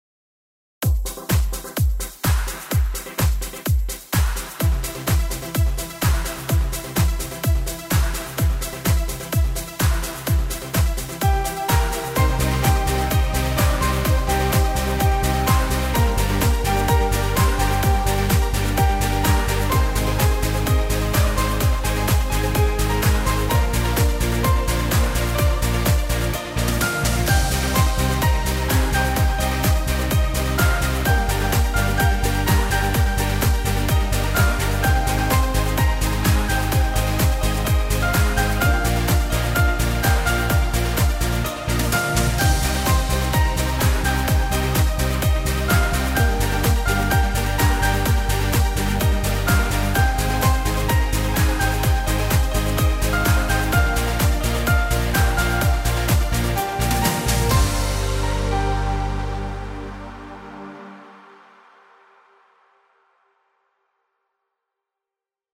Hommage an den unvergleichlichen 80er Jahre Sound.